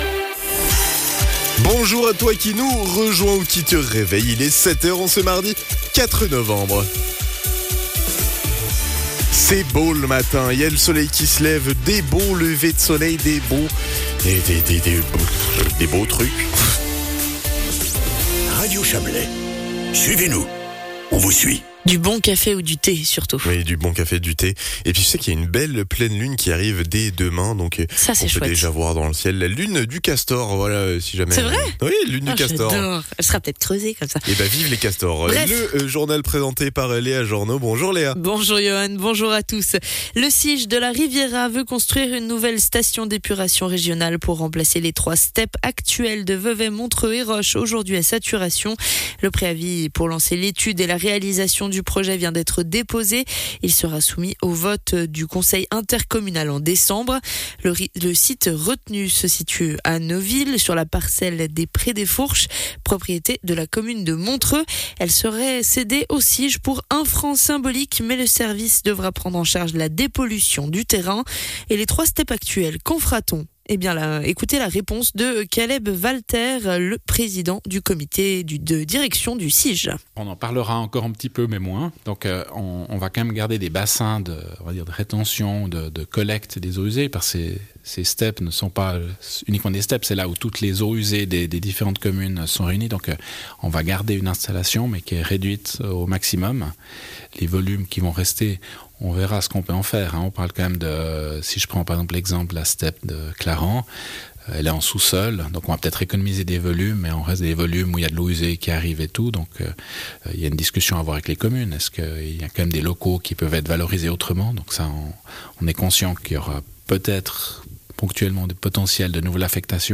Le journal de 7h00 du 04.11.2025